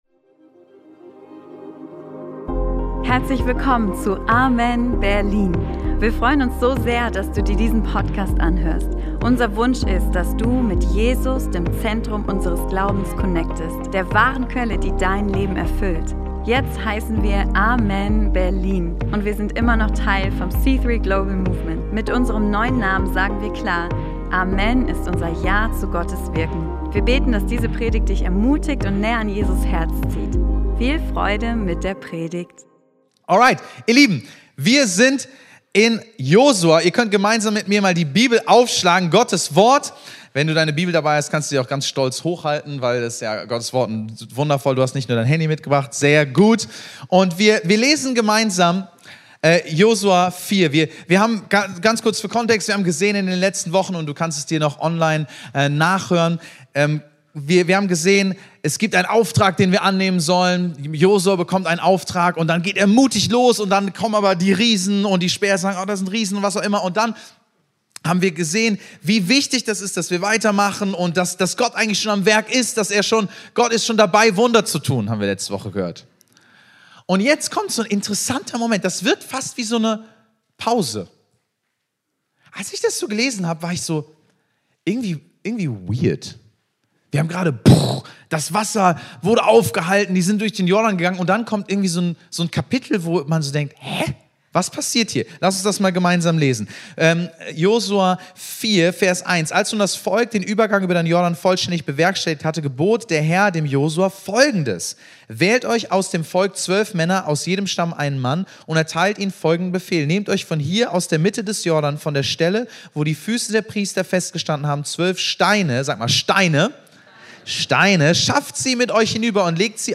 Anhand von Josua 4 zeigt Gott seinem Volk, wie wichtig es ist, innezuhalten und sich bewusst an sein Eingreifen zu erinnern, bevor die nächsten Herausforderungen kommen. Diese Predigt macht deutlich: Erinnerungen formen unsere Identität. Der Feind erinnert uns an unser Versagen – Jesus erinnert uns an seine Vergebung.